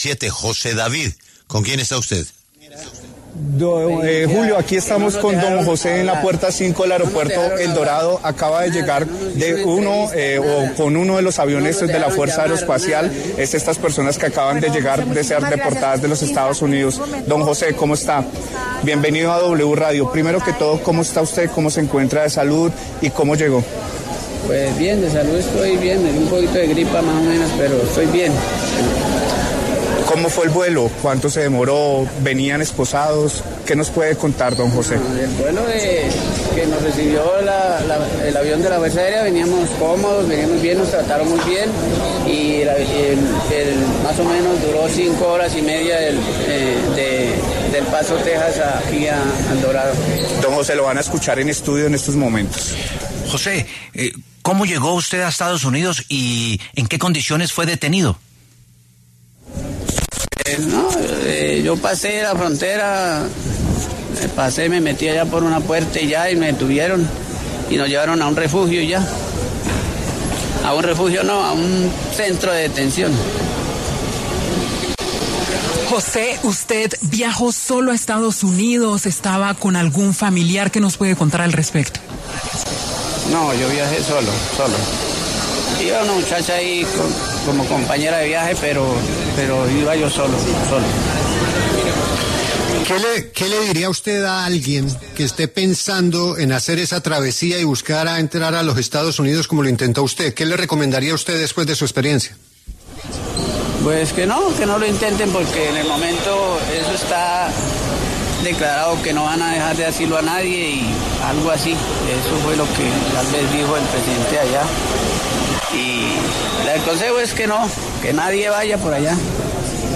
W Radio hace presencia en el Aeropuerto El Dorado, donde llegó el primer vuelo con deportados colombianos desde Estados Unidos.